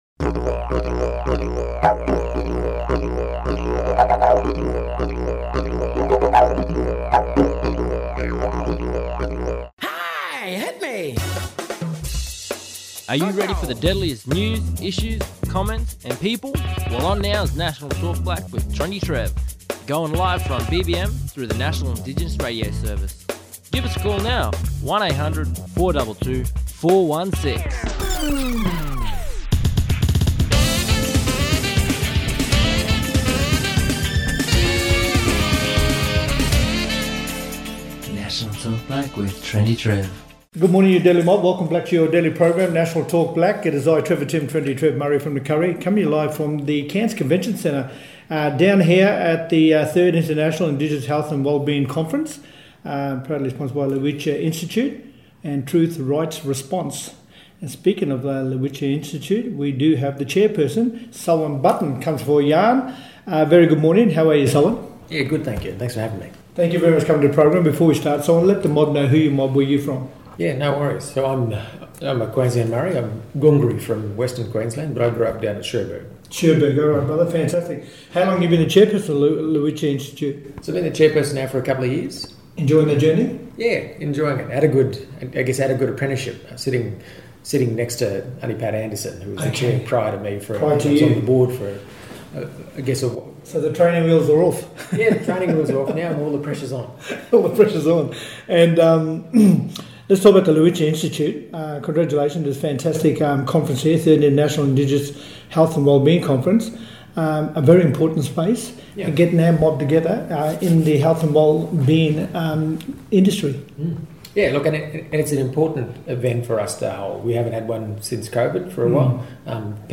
Justin Mohamed, inaugural Ambassador for First Nations People with the Department of Foreign Affairs and Trade, talking about his role and discussing the Australia’s international engagement, supports for First Nations businesses and exporters including interests in building connections across the Indo-Pacific region and Indigenous rights around the world.